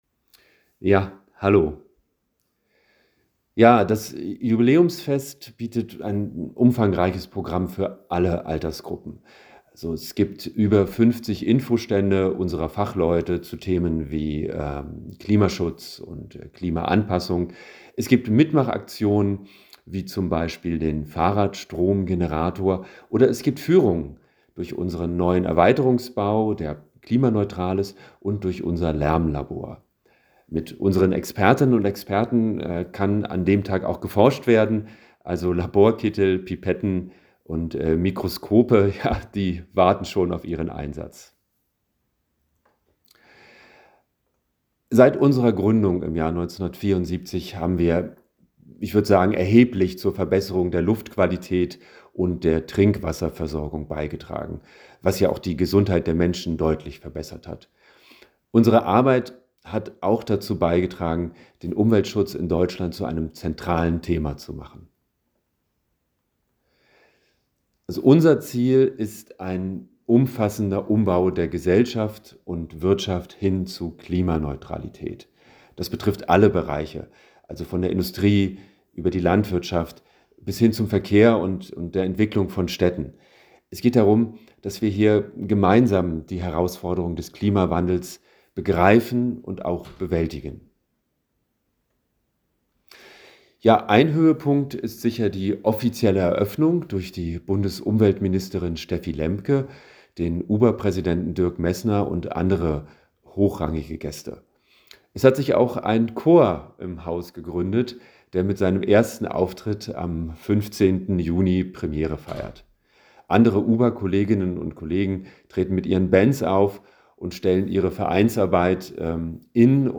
Interview: 2:50 Minuten